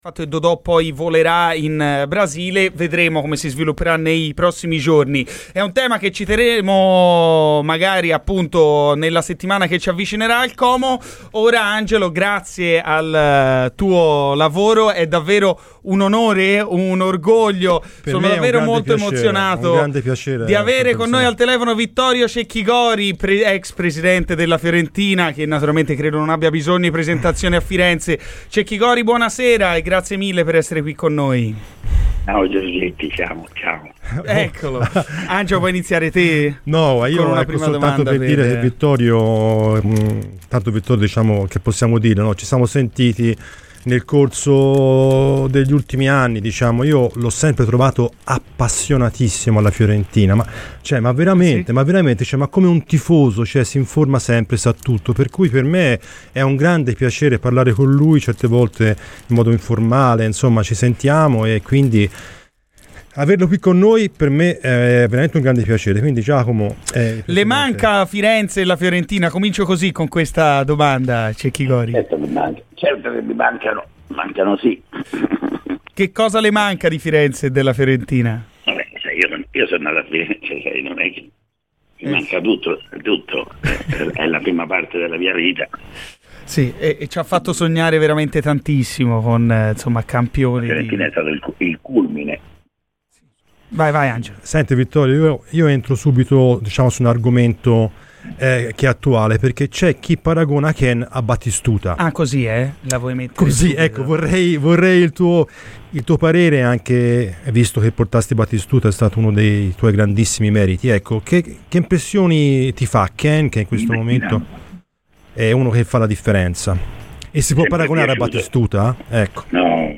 L'ex presidente della Fiorentina, Vittorio Cecchi Gori, è intervenuto su Radio Firenze Viola. Durante la trasmissione "Garrisca al Vento"